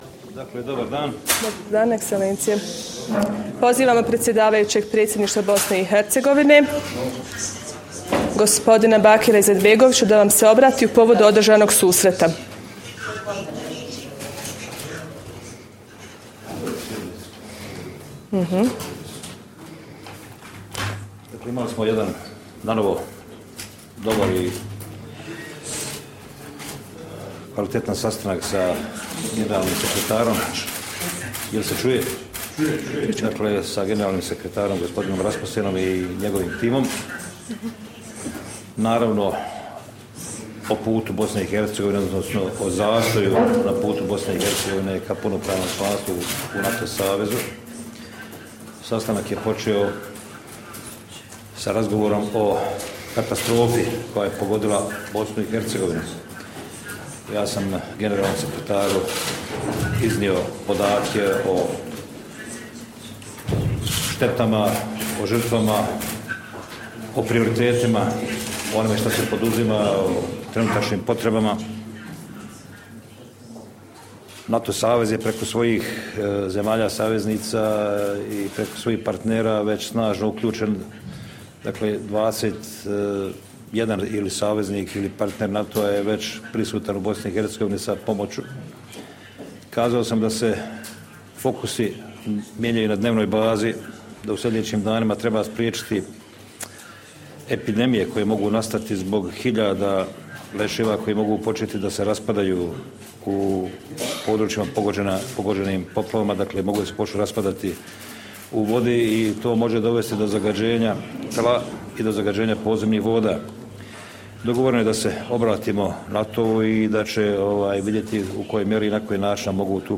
Joint press point with NATO Secretary General Anders Fogh Rasmussen and the Chair of the Bosnia & Herzegovina Presidency, Bakir Izetbegovic